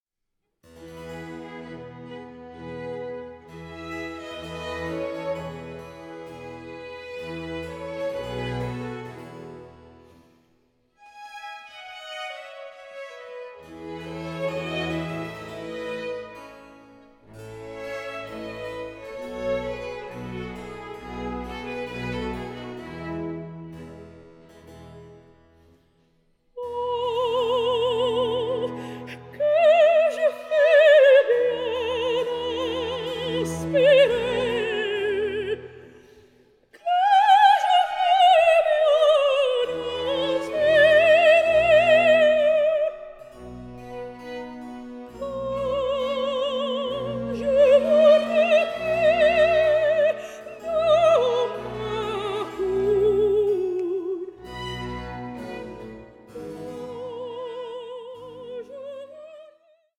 Aria of Nannetta